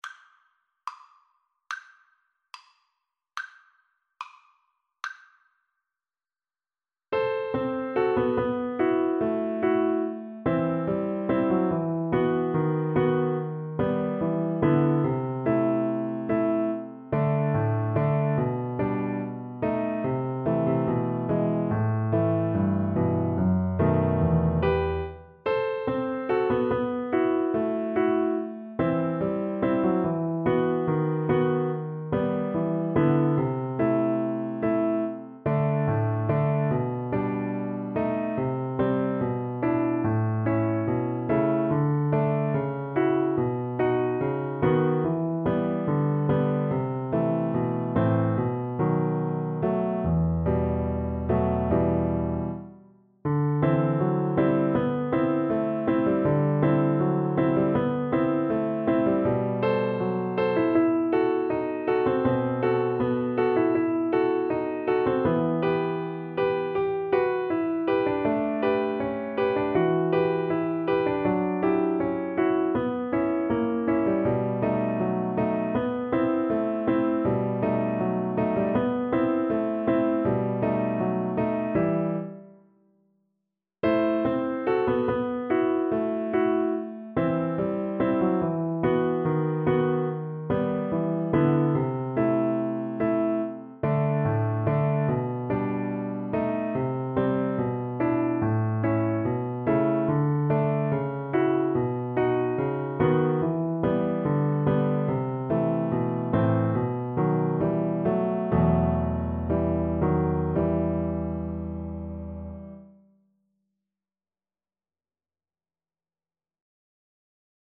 ~ = 72 Andantino (View more music marked Andantino)
Classical (View more Classical Cello Music)